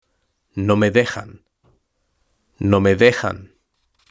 Lectura en voz alta: 3.2 Los medios de comunicación y la tecnología (H)